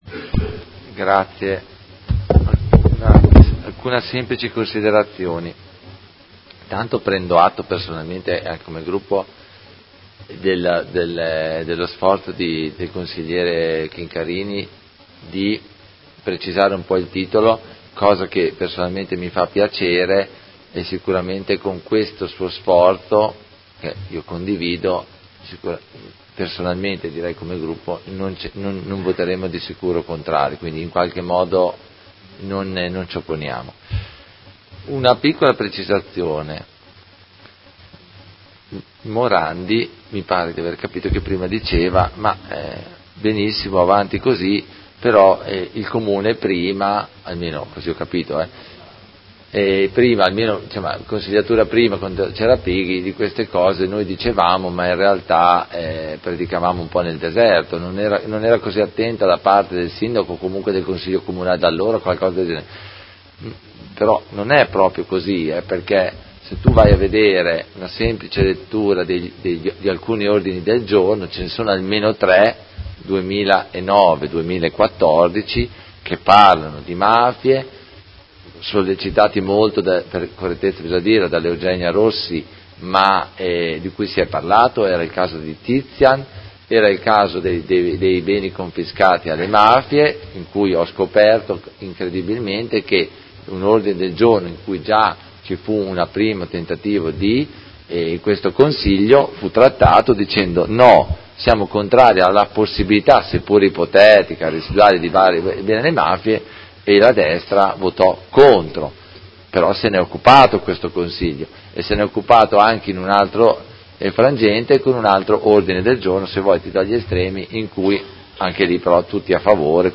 Seduta del 7/03/2019 Dibattito congiunto su Ordine del Giorno nr. 185299, Mozione nr. 61393 e Ordine del Giorno 68084